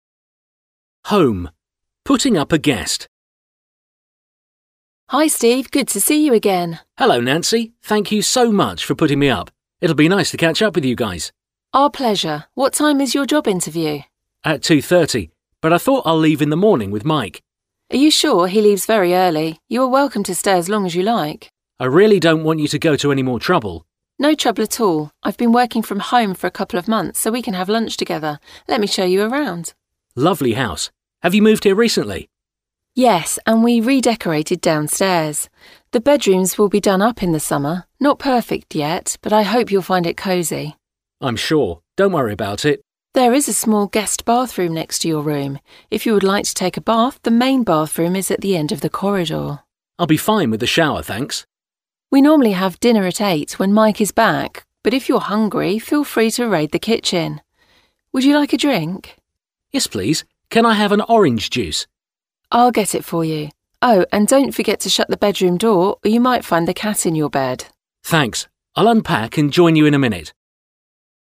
Ez a hasznos-hangos angol párbeszéd jól jöhet, ha külföldi vendéget szállásolsz el otthonodban.